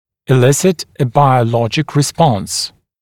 [ɪ’lɪsɪt ə ˌbaɪəu’lɔʤɪk rɪ’spɔns][и’лисит э ˌбайоу’лоджик ри’спонс]вызывать биологическую (ответную) реакцию